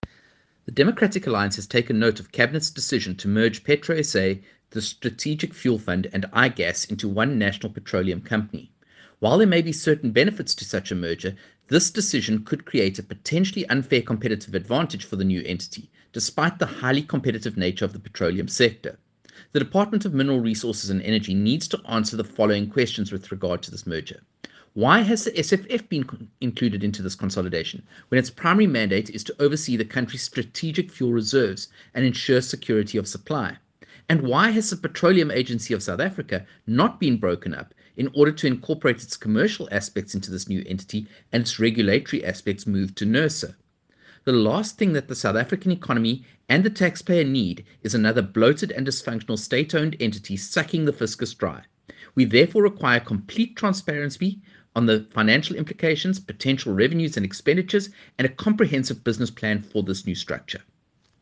soundbite by Kevin Mileham MPDA Shadow Minister of Mineral Resources and Energy